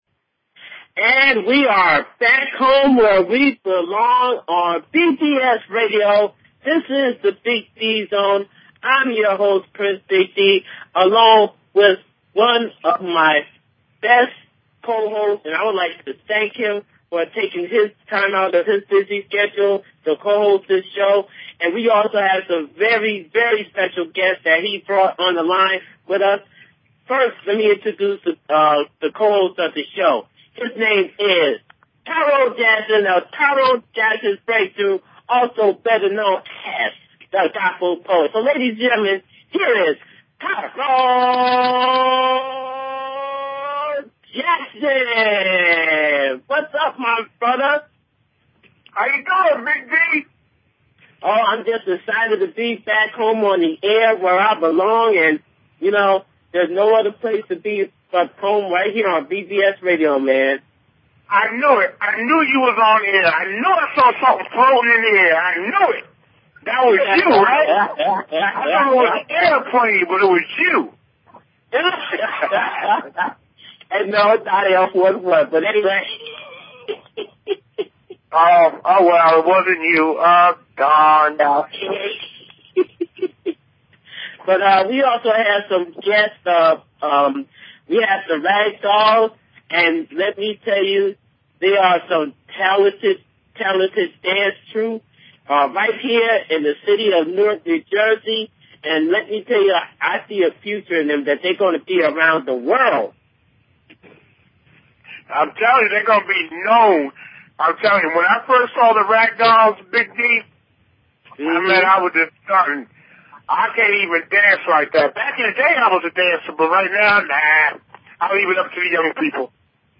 Talk Show Episode
The show will feature artists from R&B, RA, HIP HOPGOSPEL, POETRY, ROCK, AND MAY BE MORE!